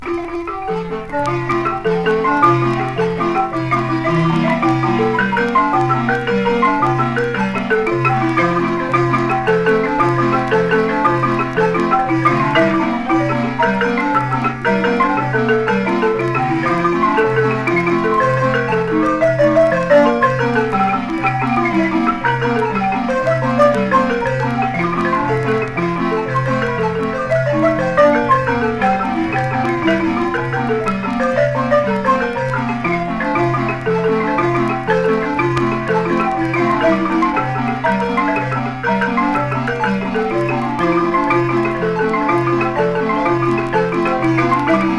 こちらは現地録音盤で、メキシコの観光都市オアハカのマリンババンドが演奏する様を収録。地元の演奏家らによる9人編成と3人編成バンドの音が録音としてはざらっとしていていながら、街の空気に響き溶けるような様が素晴らしい。打鍵の熱量、生み出されるポコポコとした音がとても心地良いです。
World, Field Recording　USA　12inchレコード　33rpm　Mono